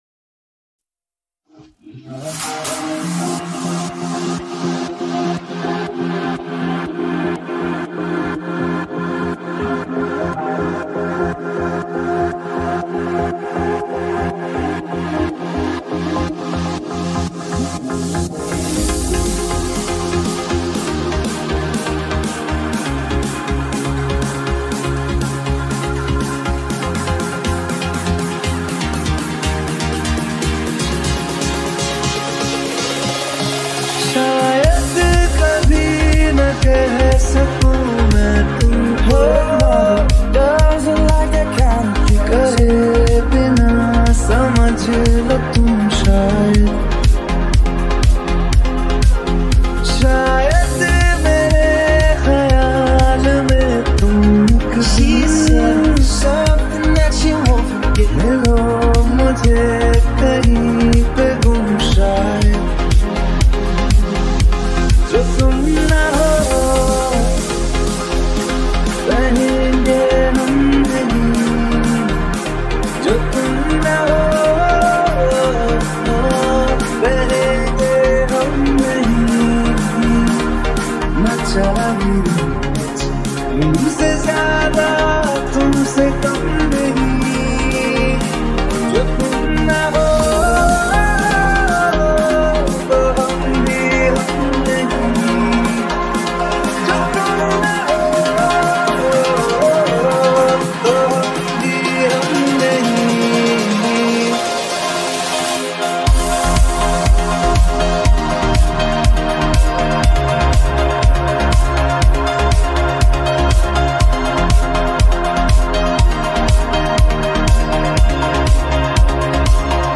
Progressive House Remake